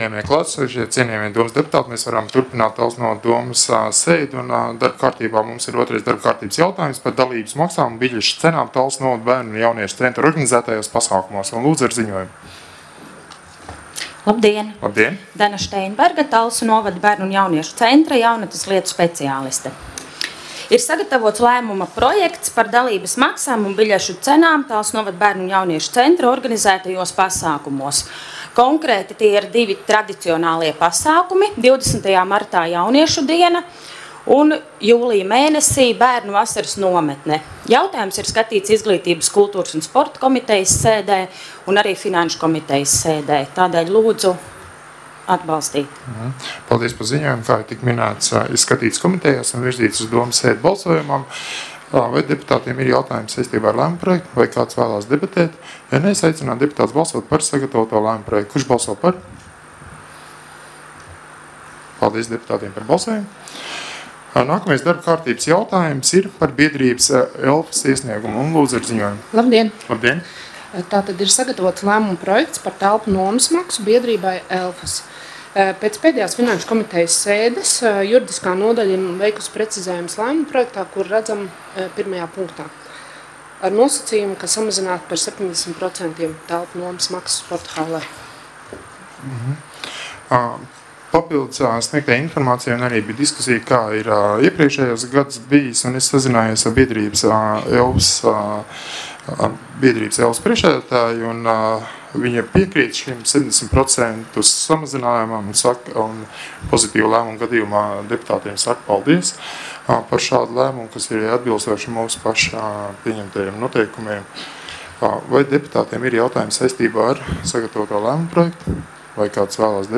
Balss ātrums Publicēts: 30.01.2020. Protokola tēma Domes sēde Protokola gads 2020 Lejupielādēt: 3.